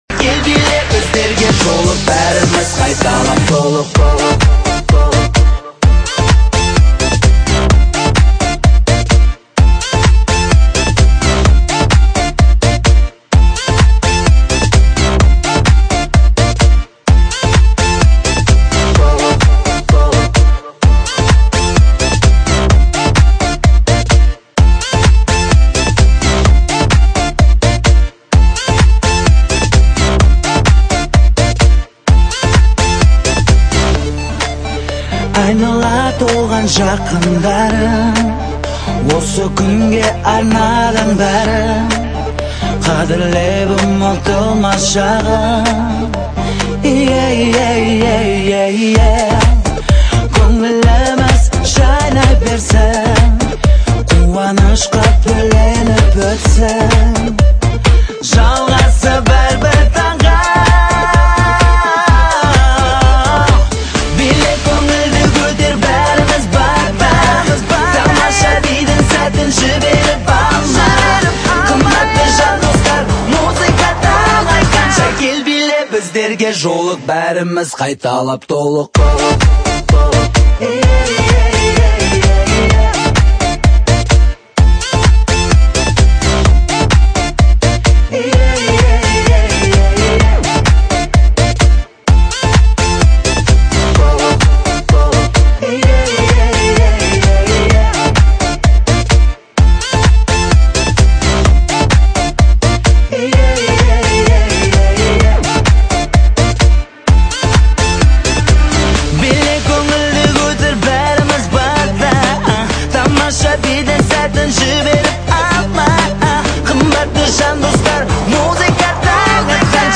DJ版